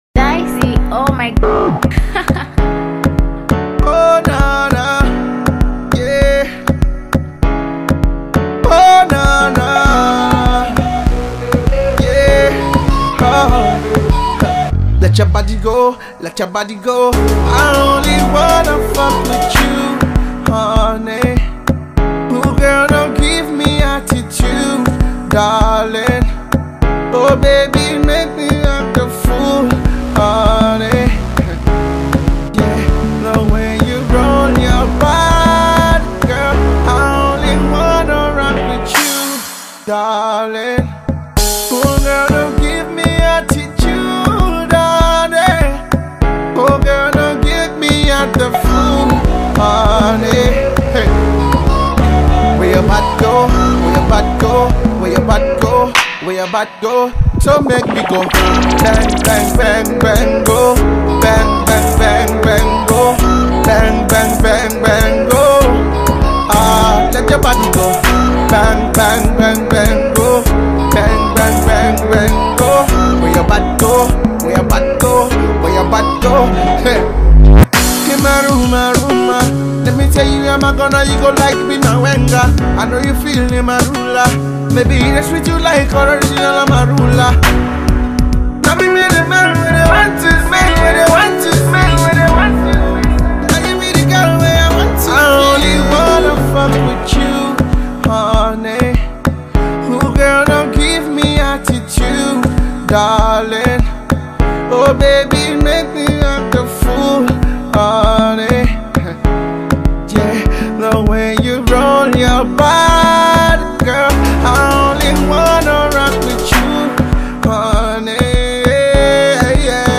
Afro-pop